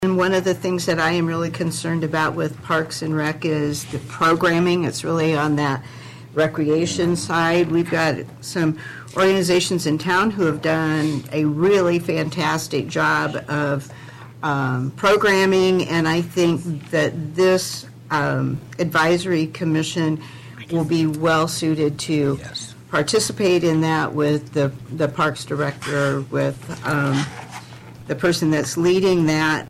Councilperson Elaine Otte says the proposed Advisory Commission would be a good addition.